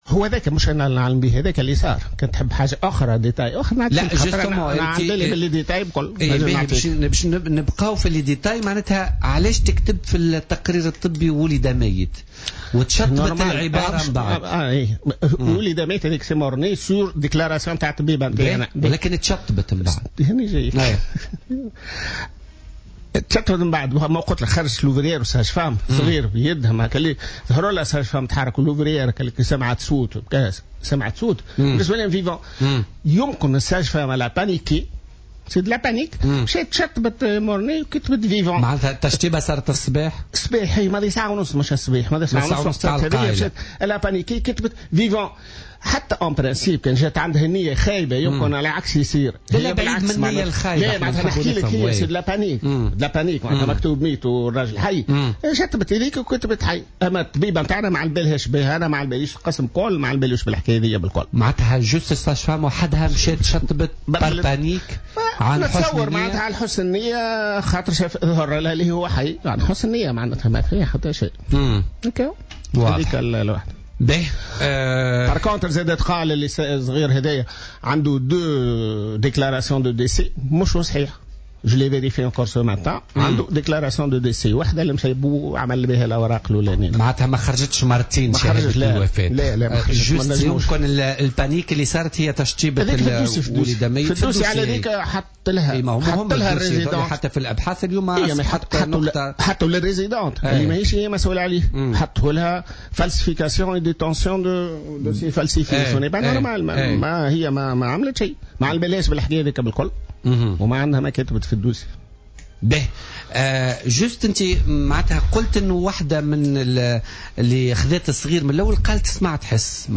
وأوضح في برنامج "بوليتيكا" على "الجوهرة أف أم" أن القابلة قد تكون قامت بشطب عبارة ولد ميتا واستبدالها بعبارة ولد حيا من باب الارتباك" بعد أن تبين ان الرضيع يتحرك عندما تسلمه والده.